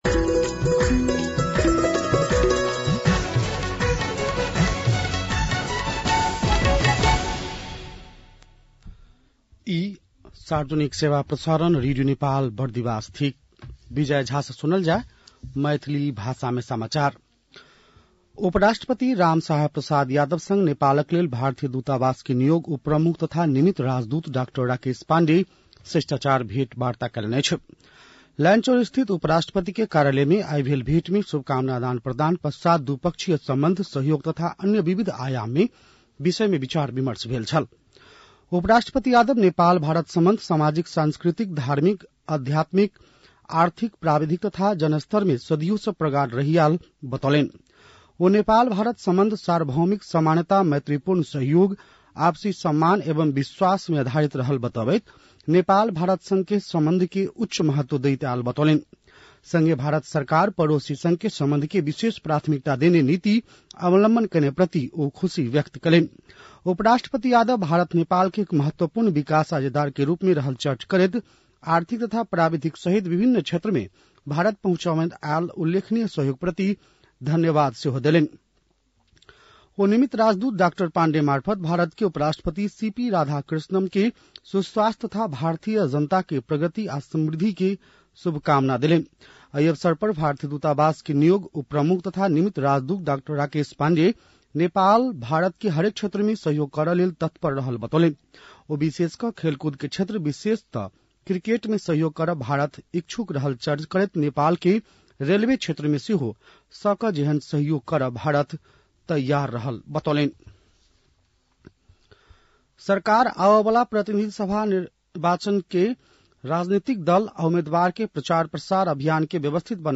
An online outlet of Nepal's national radio broadcaster
मैथिली भाषामा समाचार : ७ माघ , २०८२